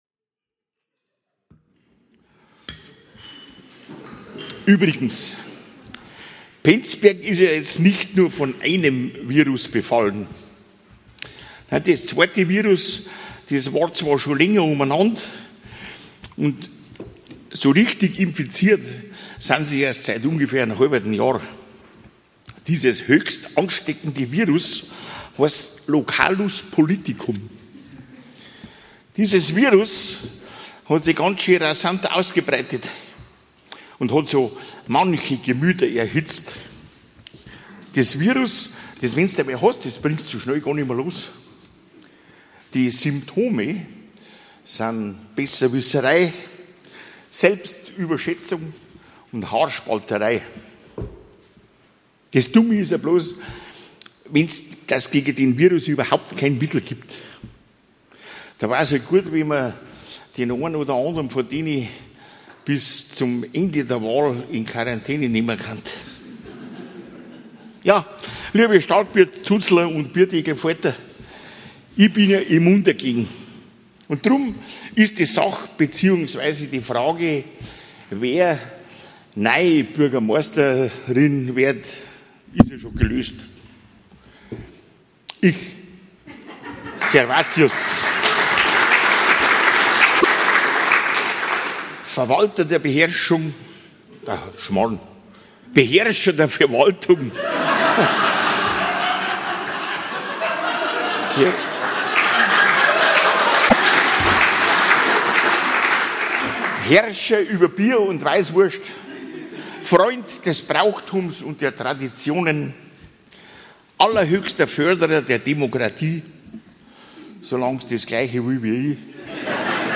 Nur noch der harte Kern der Mitglieder-Familie konnte das Ambiente liefern, ansonsten stiegen wir auf einen Live-Stream vor fast leerer Stadthalle um.
Starkbierpredigt – „Von Viren und Kandidatenvermehrung“: 4:51 min.